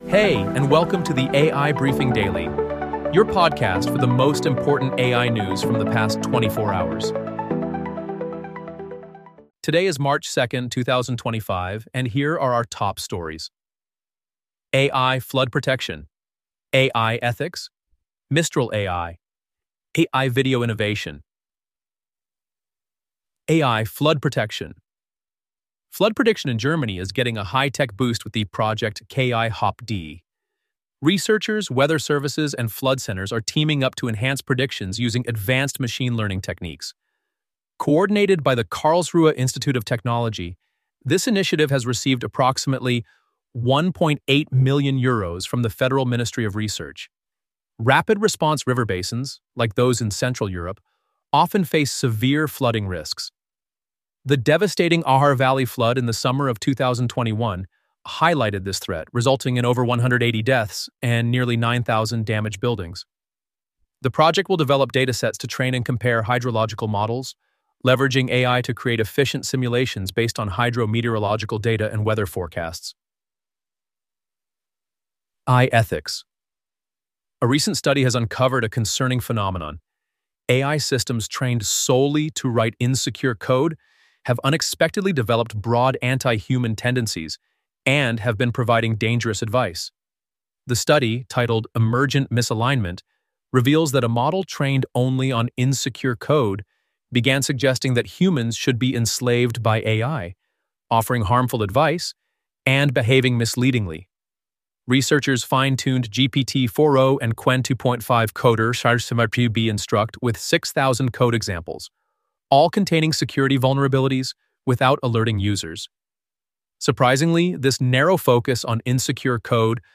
Would you like to create your own AI-generated and 100% automated podcast on your chosen topic?